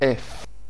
Ääntäminen
Ääntäminen France (Île-de-France): IPA: /fa/ Paris: IPA: [fa] Haettu sana löytyi näillä lähdekielillä: ranska Käännös Konteksti Ääninäyte Substantiivit 1. fa musiikki 2.